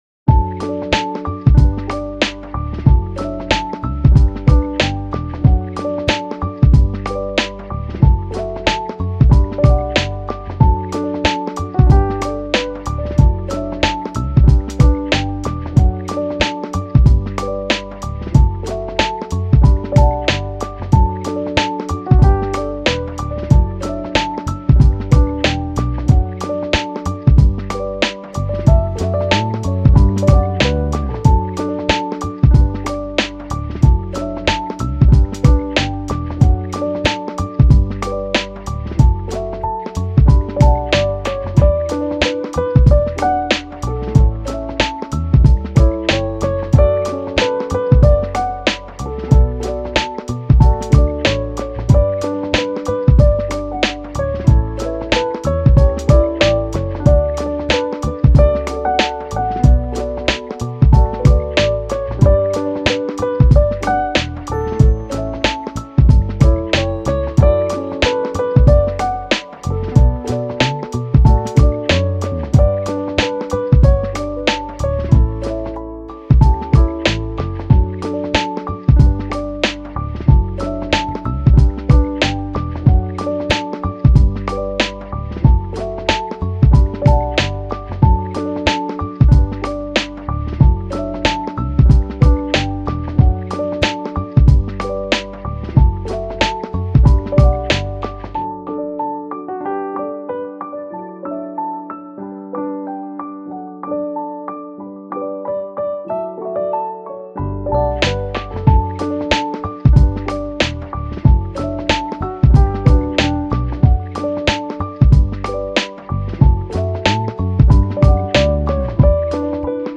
チル・穏やか
明るい・ポップ